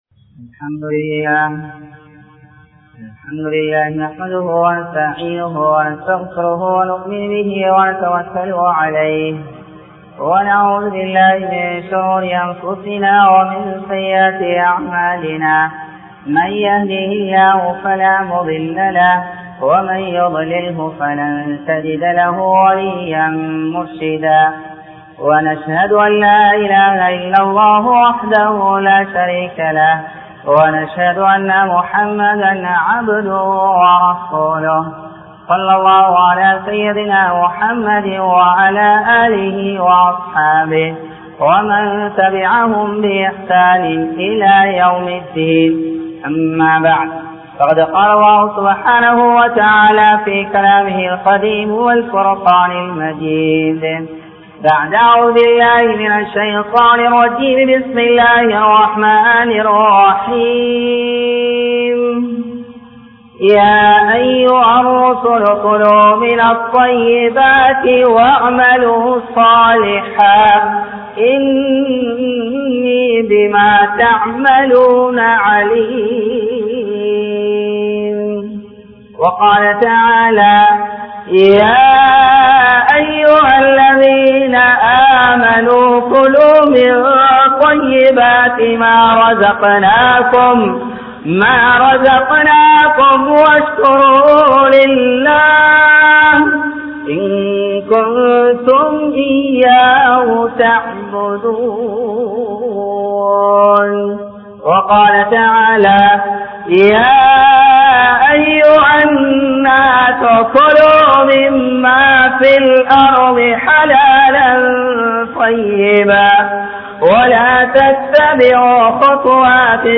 Halal & Haram | Audio Bayans | All Ceylon Muslim Youth Community | Addalaichenai